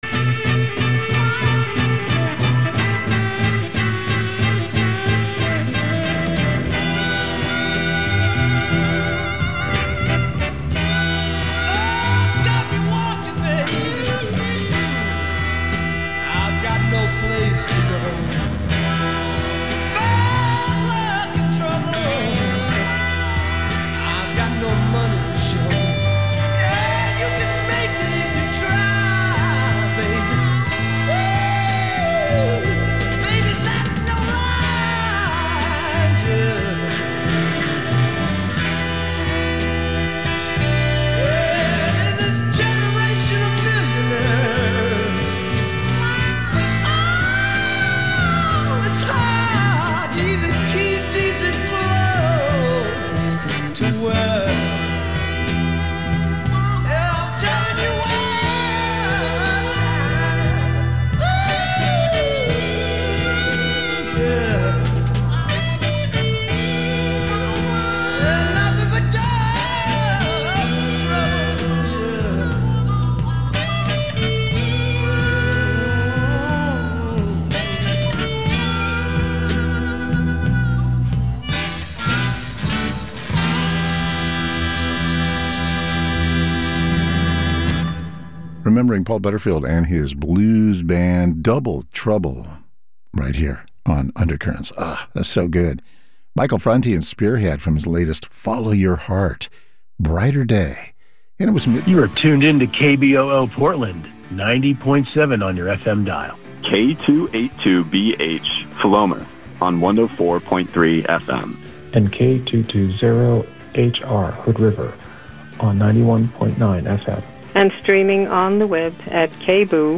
Conversations with leaders in personal and cultural transformation